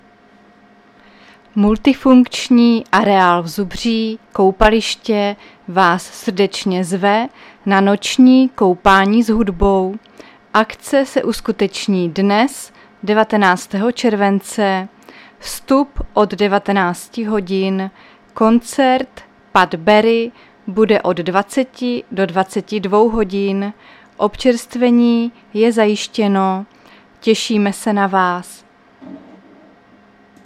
Záznam hlášení místního rozhlasu 19.7.2024
Zařazení: Rozhlas